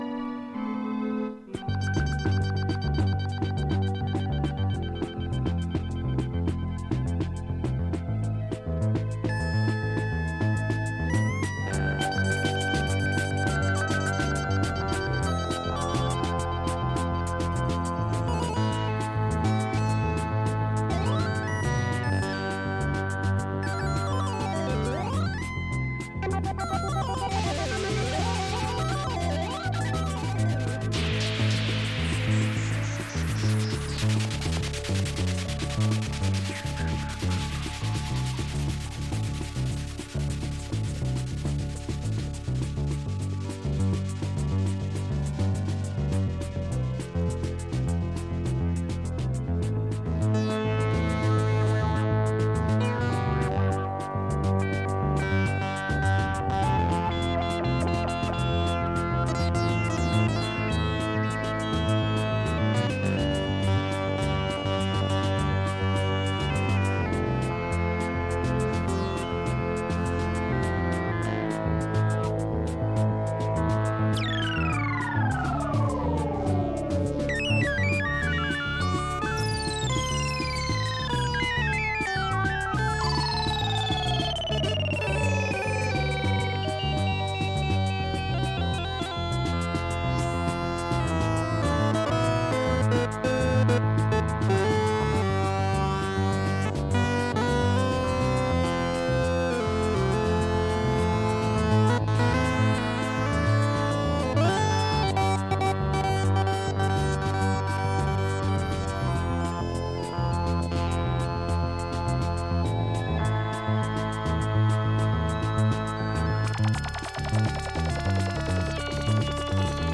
electronic experimentalism
Roland Synthesizers, Drum Machines and field recordings